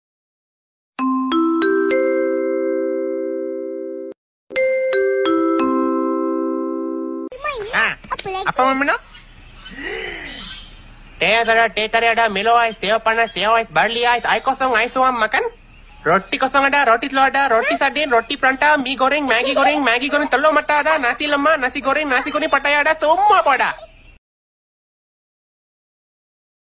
Genre: Nada notifikasi